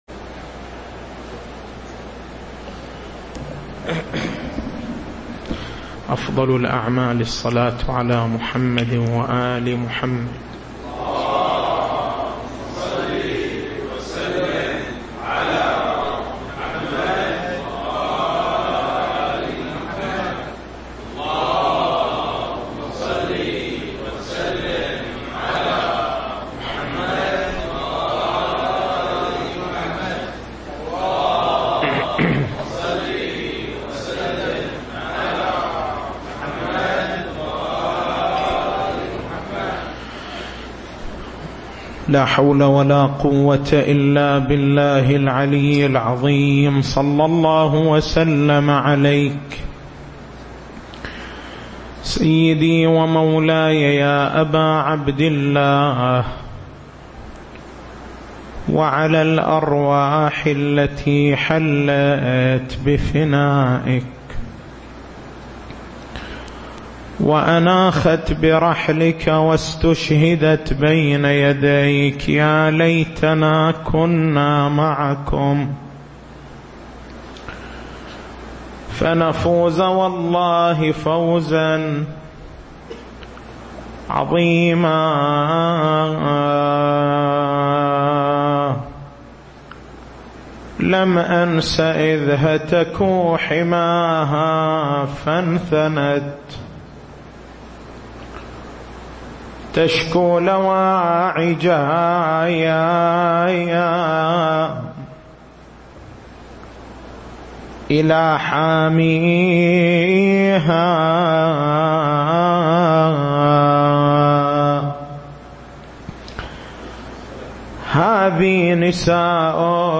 تاريخ المحاضرة: 26/09/1435 نقاط البحث: التعريف بأصحاب السبت وبيان سبب عقوبتهم بيان المقصود من عقوبة المسخ هل يمكن أن تقع هذه العقوبة في هذه الأمة؟